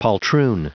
Prononciation du mot poltroon en anglais (fichier audio)